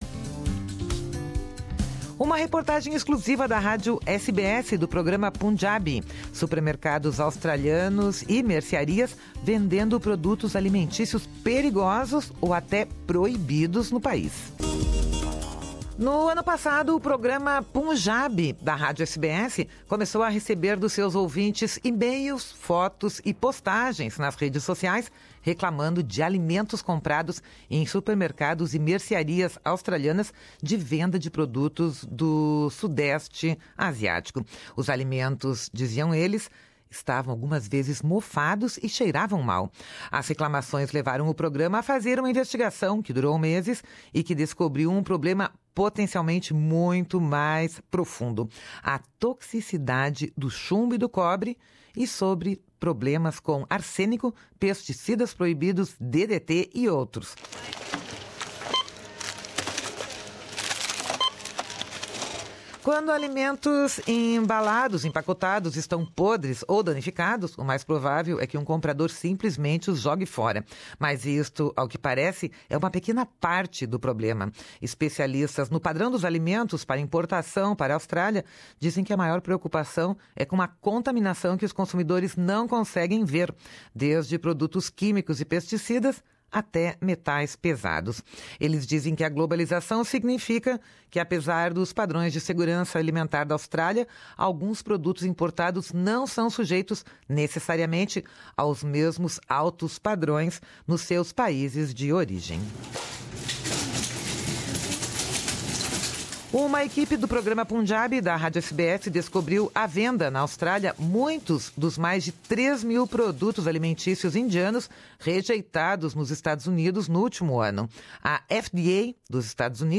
Reportagem exclusiva do Programa Punjabi da Rádio SBS.